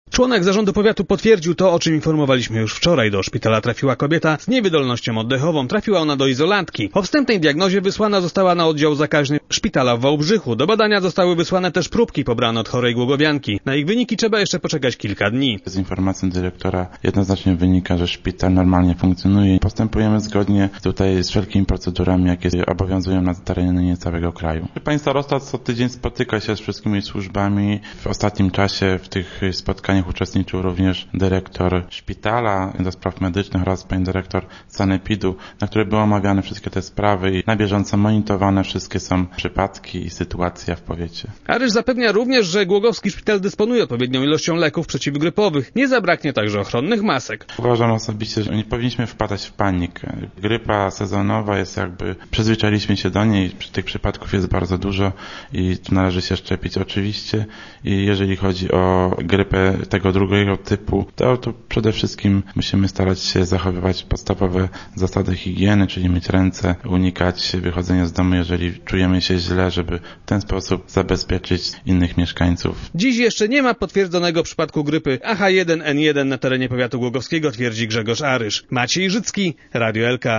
Jeśli chodzi o przygotowania do ewentualnej epidemii, to postępujemy zgodnie z procedurami, jakie obowiązują na terenie całego kraju - mówił na radiowej antenie Grzegorz Aryż.